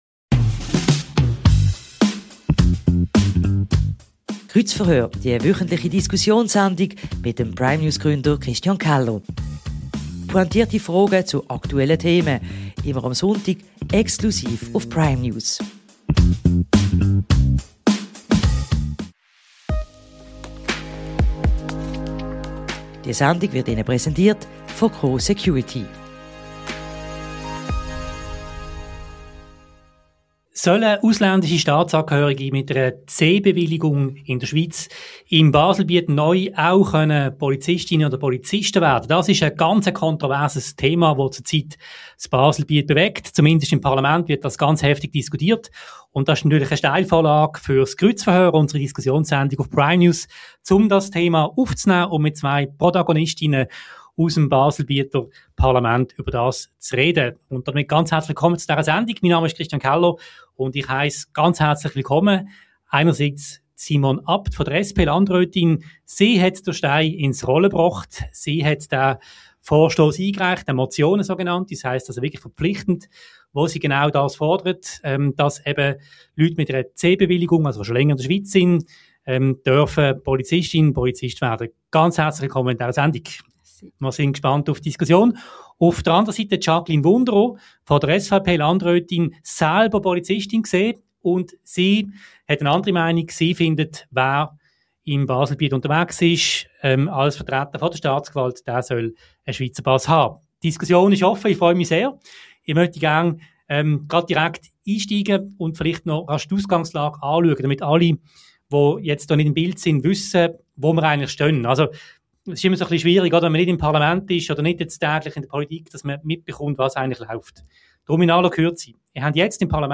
Im Kreuzverhör diskutieren Simone Abt (SP) und Jaqueline Wunderer (SVP) über die Anpassung der Zulassungskriterien.